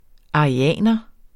Udtale [ ɑʁiˈæˀnʌ ]